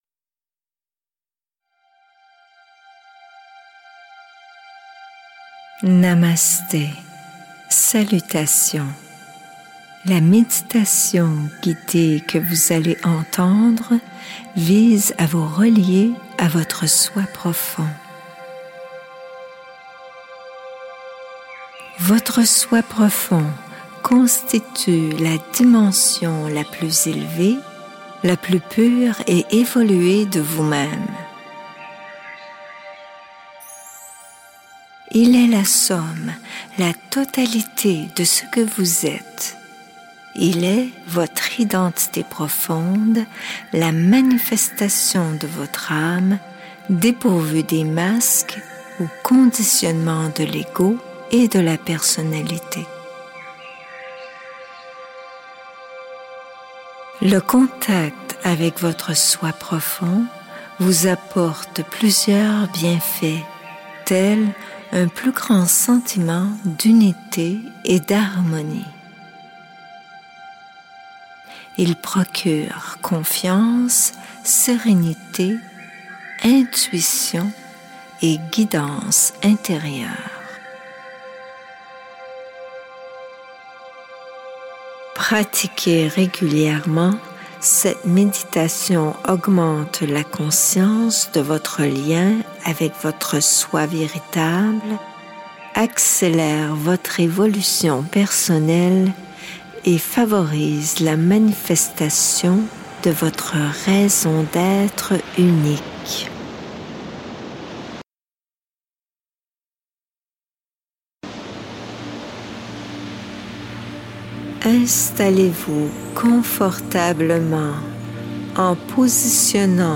Méditation Guidée - Spiritualité de l'Être, Méditation, Alignement d'âme